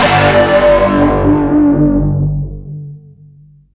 Session 11 - Note Hit.wav